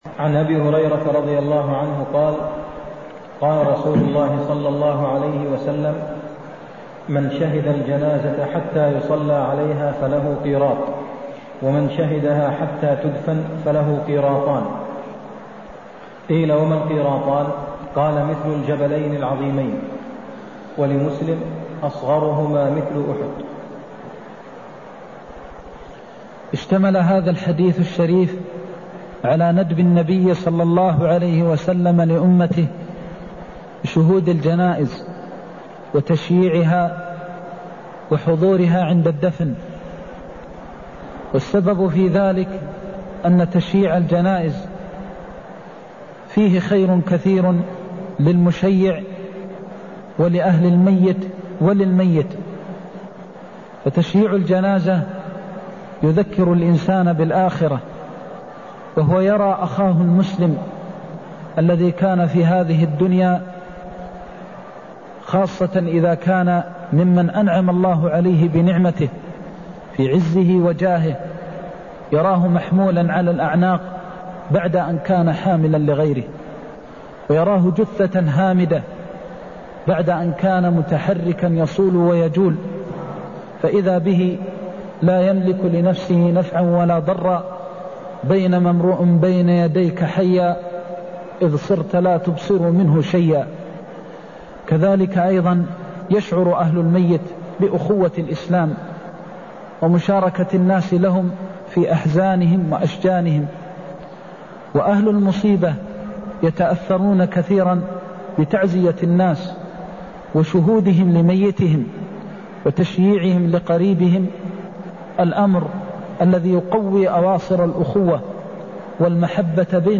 المكان: المسجد النبوي الشيخ: فضيلة الشيخ د. محمد بن محمد المختار فضيلة الشيخ د. محمد بن محمد المختار أجر المصلي على الجنازة ومتبعها (161)